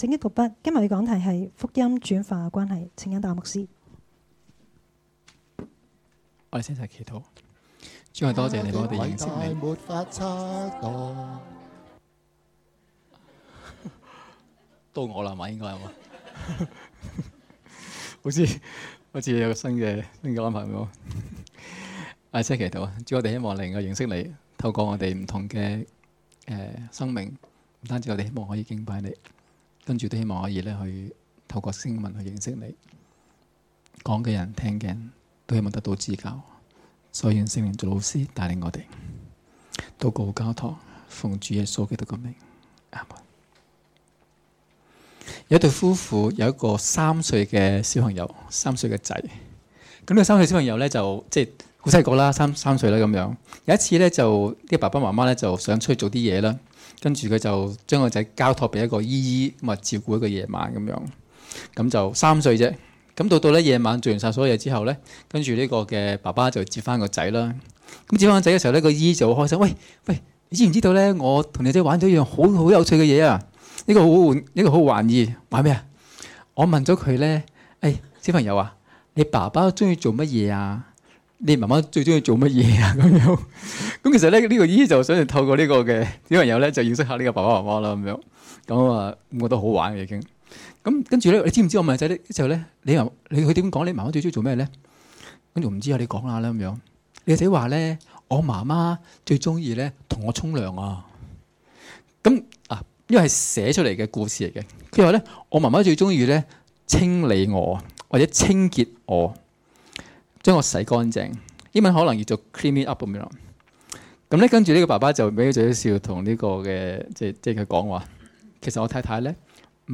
2016年11月19日及20日崇拜講道